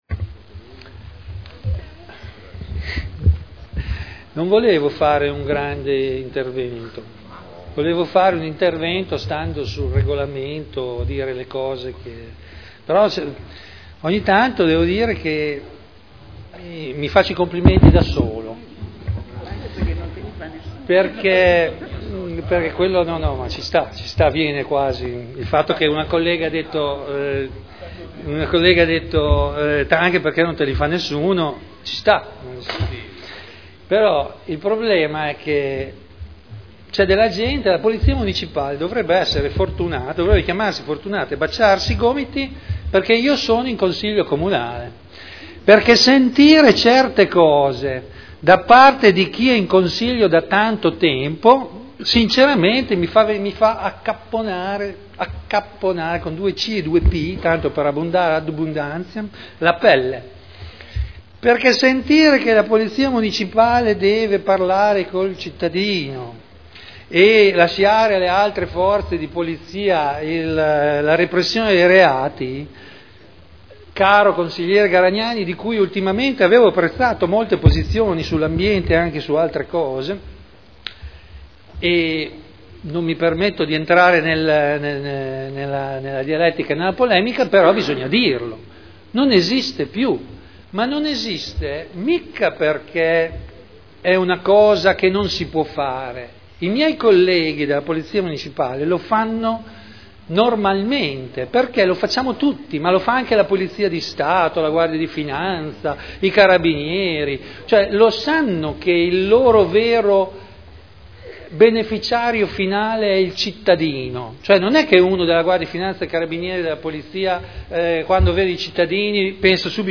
Seduta del 05/12/2011. Dibattito.